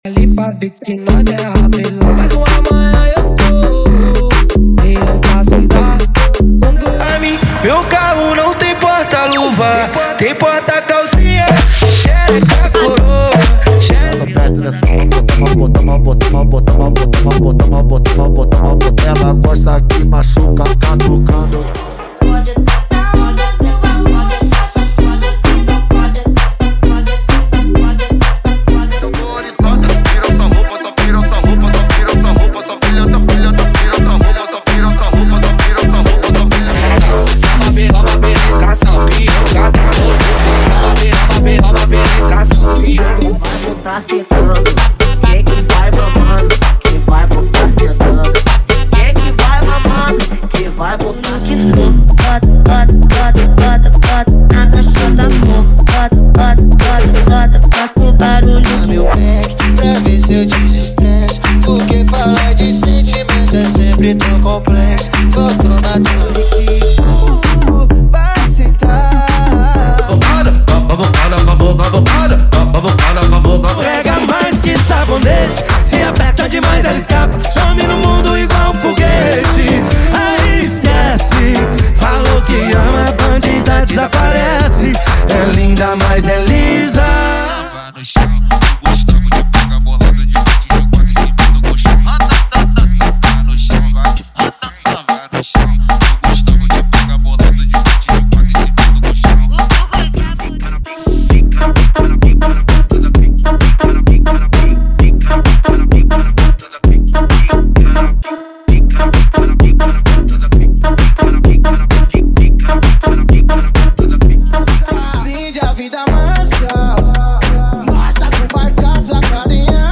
Os melhores remix estão aqui!
– Sem Vinhetas